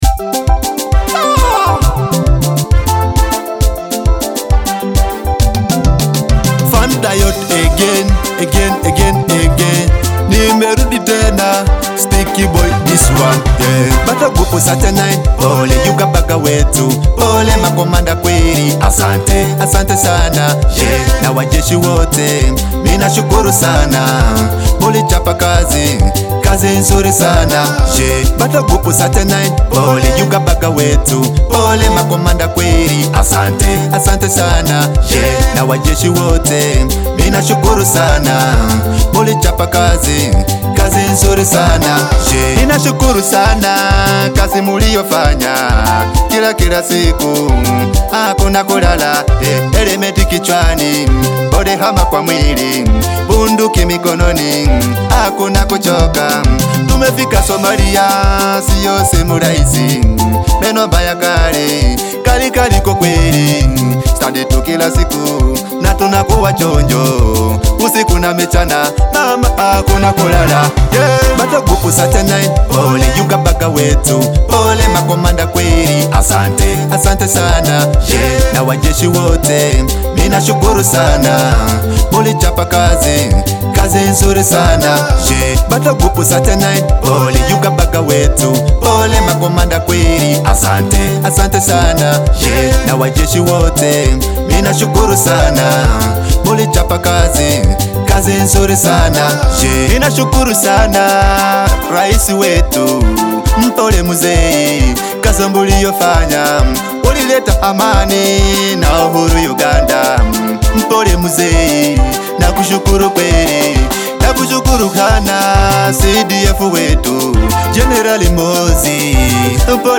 sincere delivery and proud tone